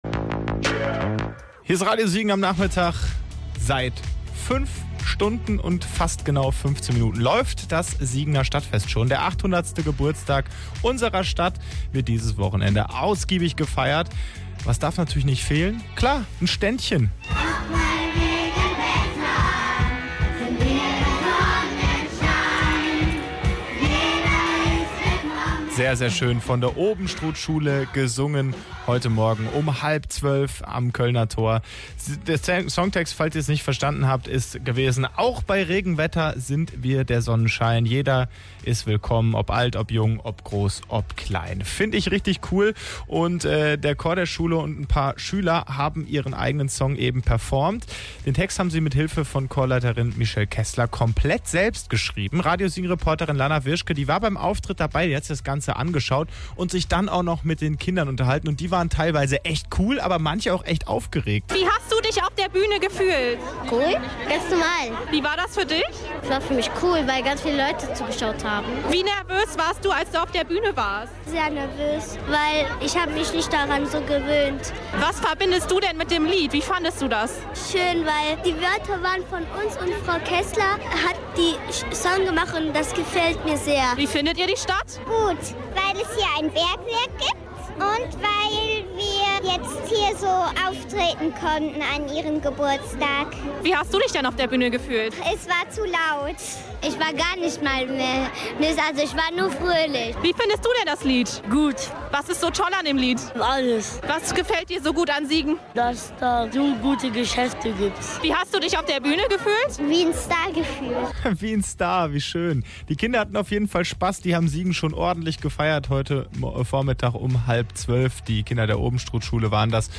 Im Rahmen der Eröffnung des Siegener Stadtfestes 2024 konnten die Kids der Obenstruthschule ihr musikalisches Talent präsentieren. Auf der Bühne am Kölner Tor   standen 130 Kinder und sangen ihr selbstkomponiertes Lied „Wir feiern Dich, Siegen, und das soll jeder wissen“.
Hier noch der Beitrag zum Auftritt von Radio Siegen: